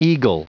Prononciation du mot eagle en anglais (fichier audio)
Prononciation du mot : eagle